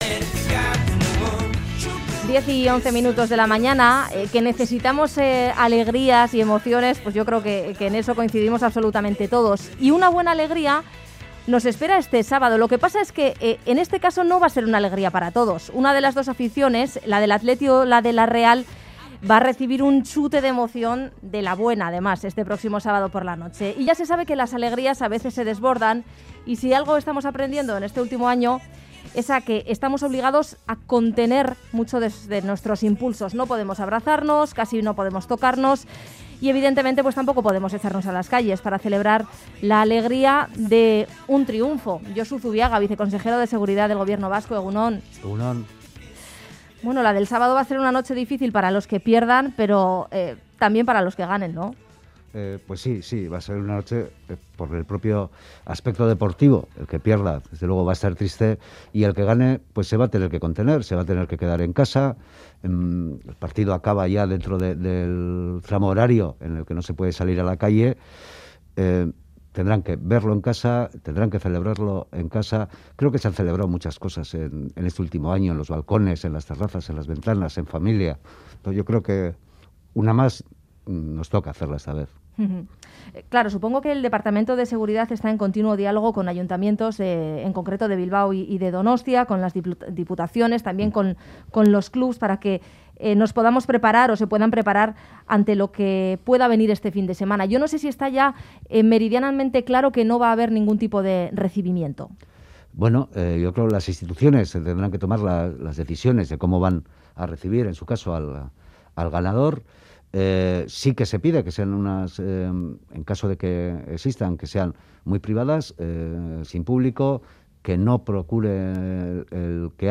Audio: Josu Zubiaga Viceconsejero de Seguridad del Gobierno Vasco explica que la ciudadanía está preocupada por el virus y colabora cuando ve incumplimientos.